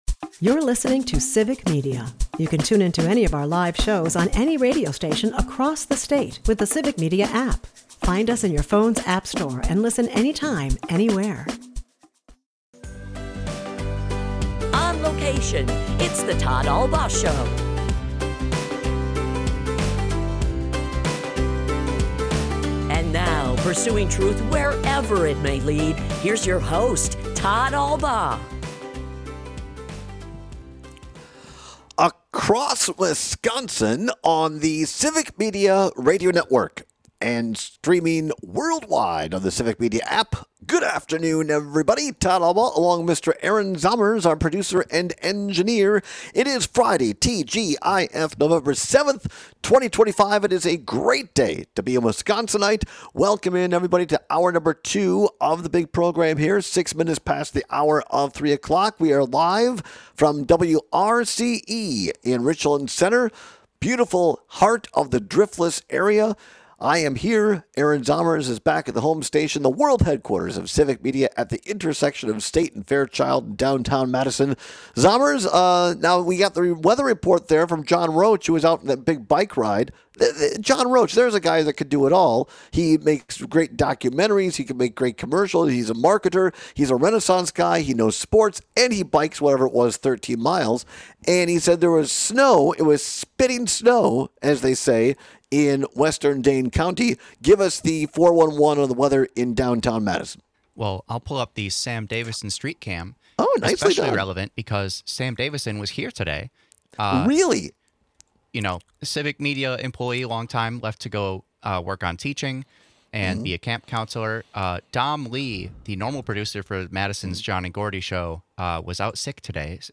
We take some very passionate calls and texts on which devices are worse to make calls and send texts on.
is a part of the Civic Media radio network and airs live Monday through Friday from 2-4 pm across Wisconsin.